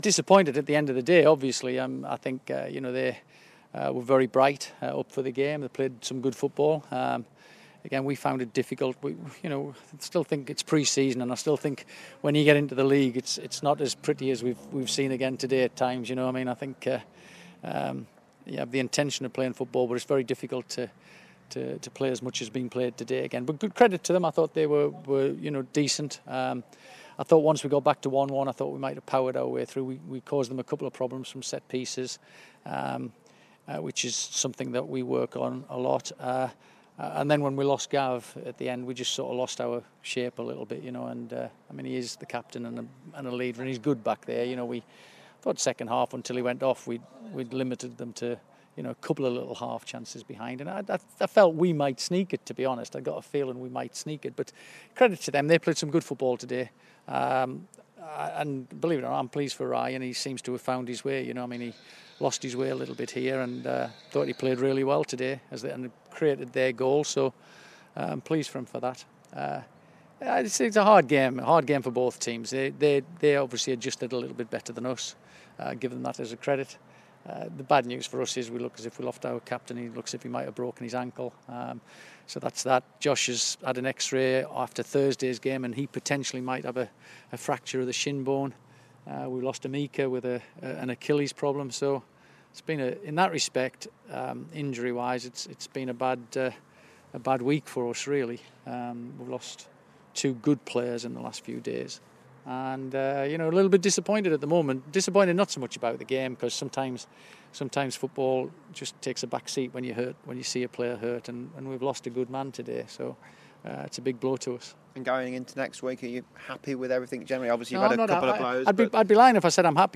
Brian Little v Boston United interview